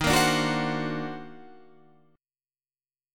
D#mM13 Chord
Listen to D#mM13 strummed